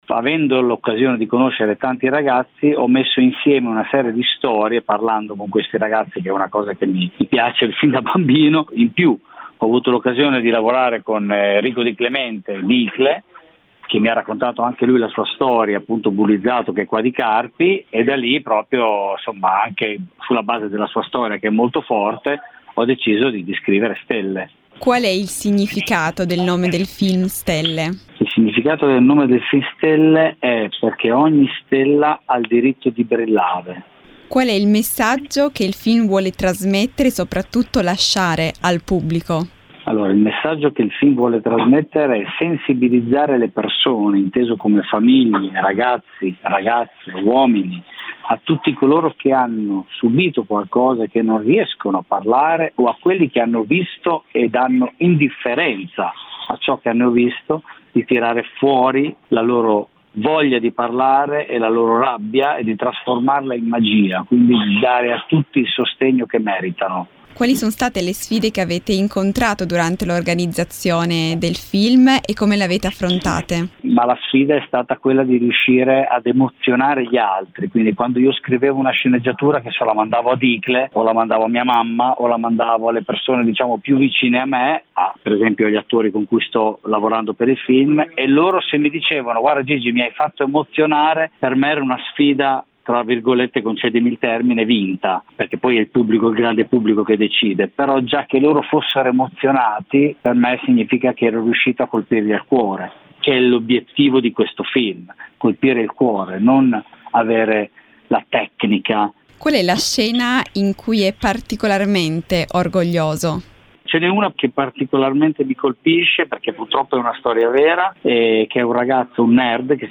Home Magazine Interviste Inizia il castinge per “Stelle contro il bullismo”